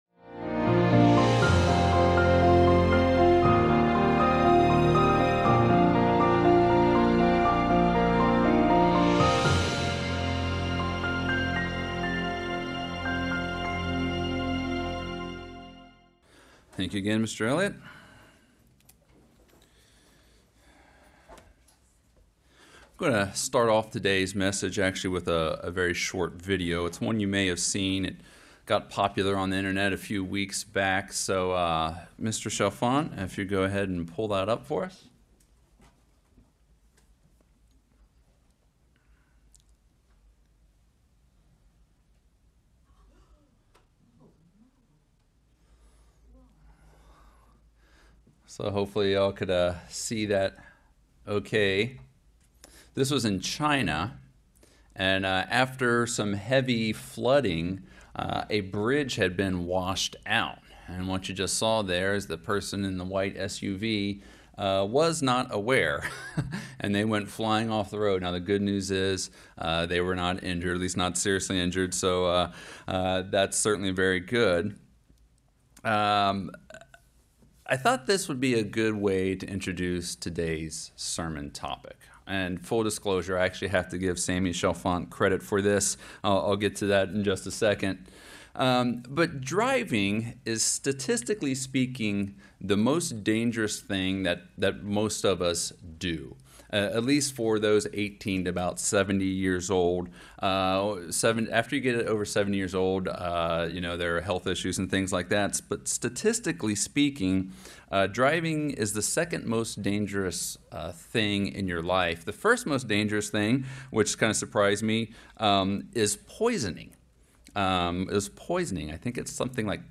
Sermons
Given in Charlotte, NC Columbia, SC Hickory, NC